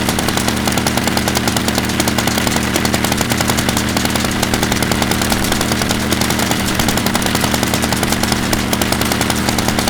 small_engine.wav